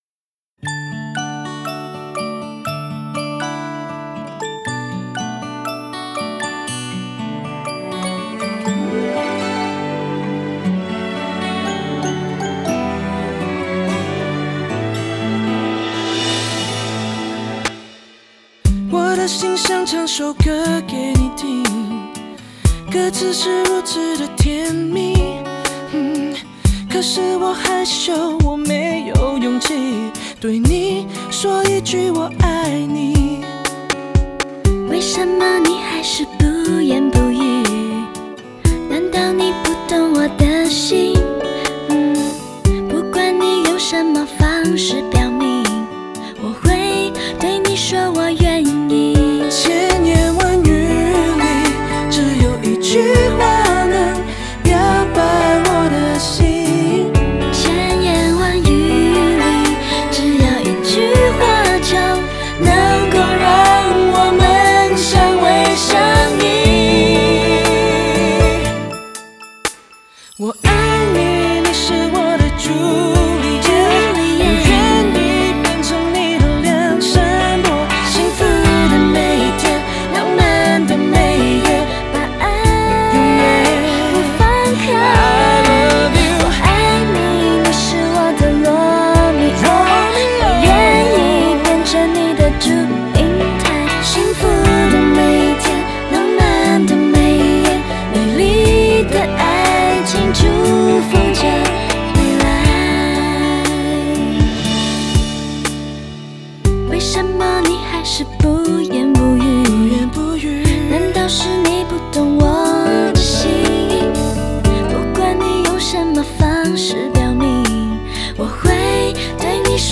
很赞的情歌对唱·~
节奏很喜欢..